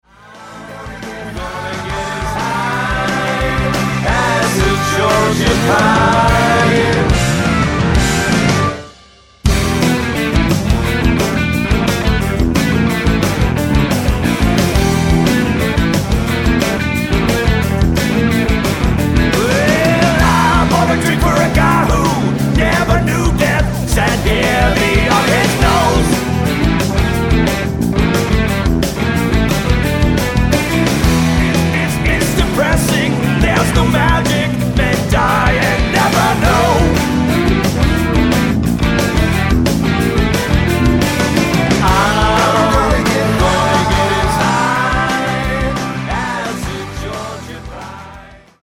keyboards, backing vocals
guitars, lead and backing vocals
drums, percussion, backing vocals
bass, backing vocals
alto and baritone sax
trumpet
trombone